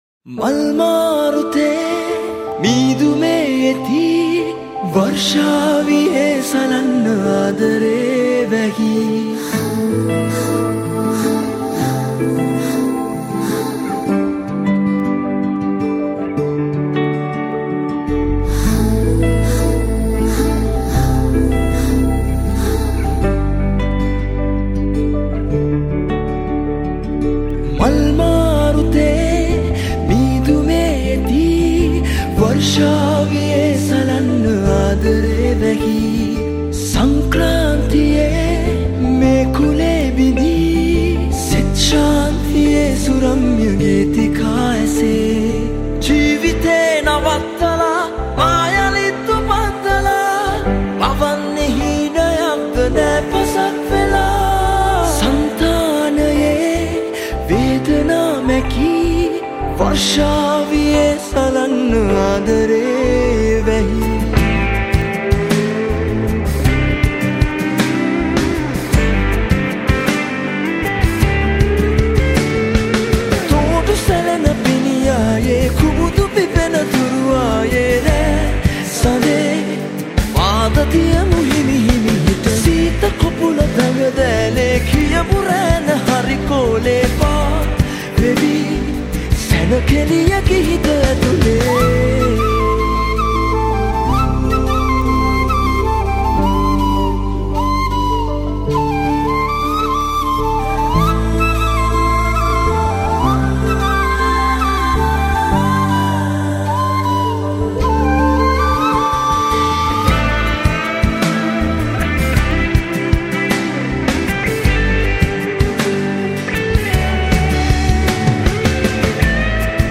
Guitar
Flute